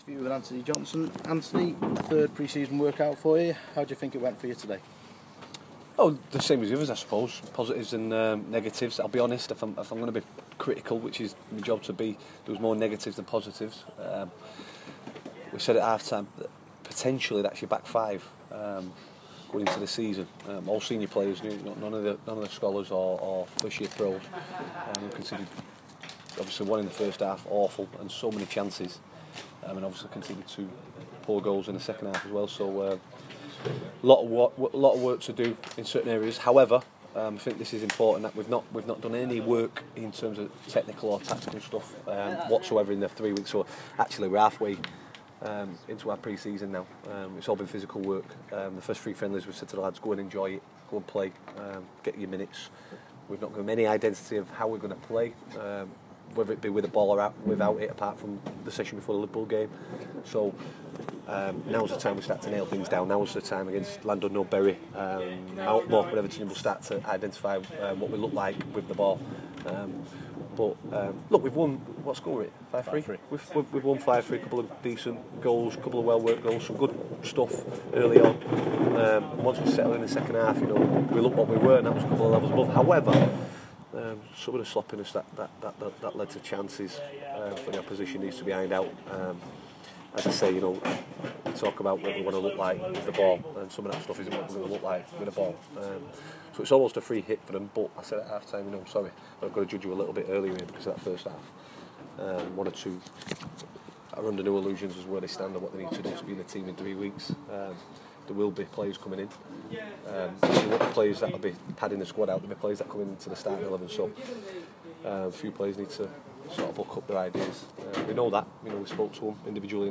speaks to the press following Runcorn Linnets pre season win.